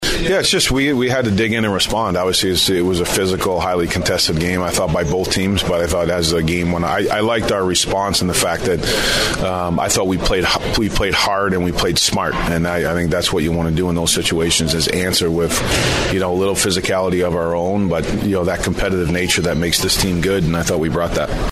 In injury news, Mats Zuccarello took a hit up high late in the first period and did not return. Wild heead coach John Hynes with his view on the hit on Zuccarello and the response by his team after.